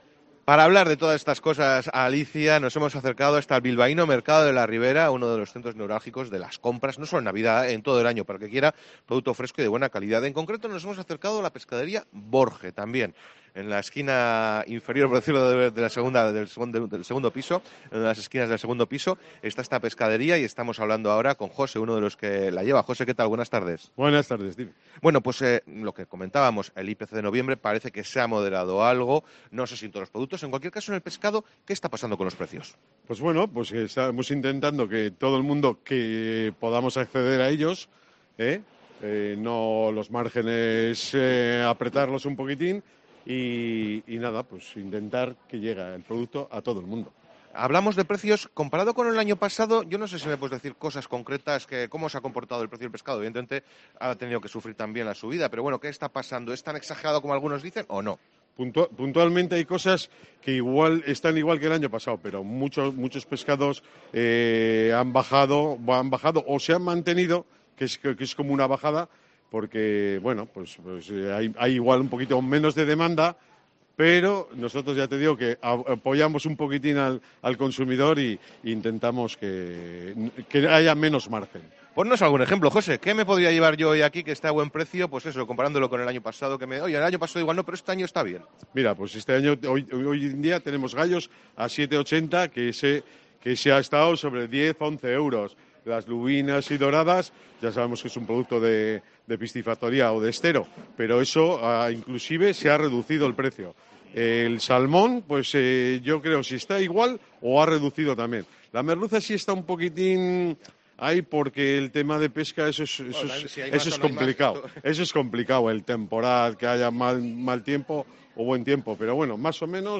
COPE País Vasco comprueba la evolución de los precios en el mercado de la Ribera de Bilbao
COPE País Vasco en el mercado de la Ribera